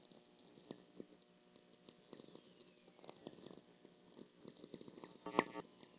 Spring Brook Township, Pennsylvania